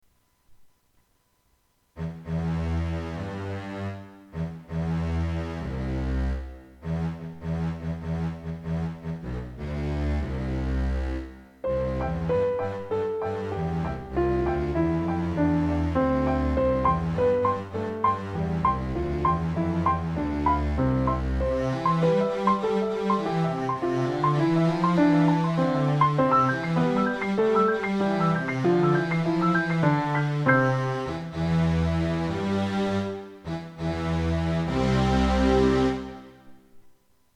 Pro tento týden jsem si pro vás připravil opět několik klavírních doprovodů písniček, které si můžete zazpívat třeba i se sourozenci nebo rodiči.